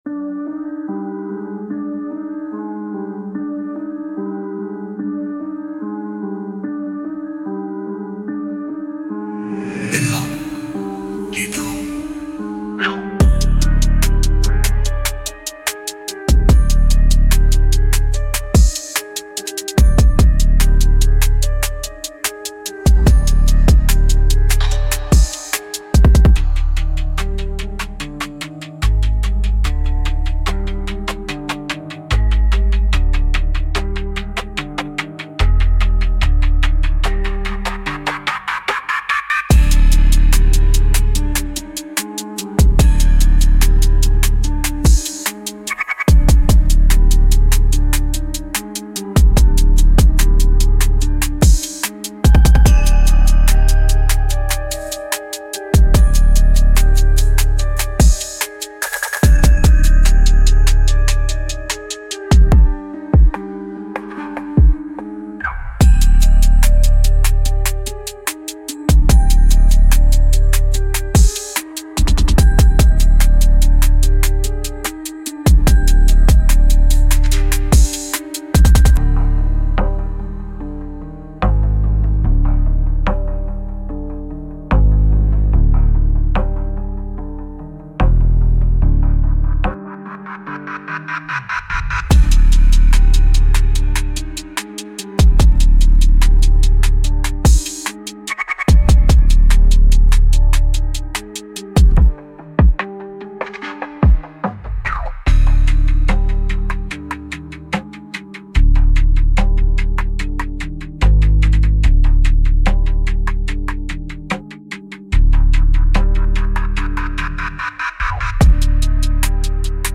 Instrumental - Real Liberty Media DOT xyz -- 4 mins